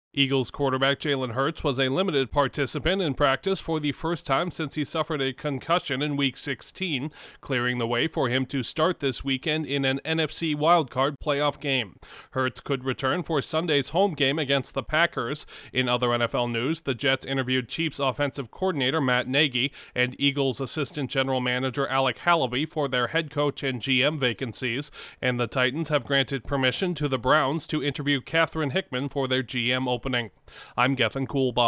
A multi-time Pro Bowl quarterback is nearing a return for his team’s playoff opener. Correspondent